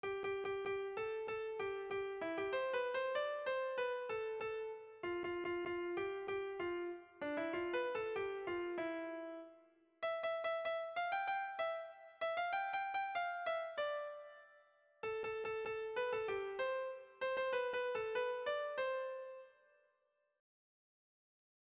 Kontakizunezkoa
Eibar < Debabarrena < Gipuzkoa < Euskal Herria
Zortziko txikia (hg) / Lau puntuko txikia (ip)
A1A2A3A4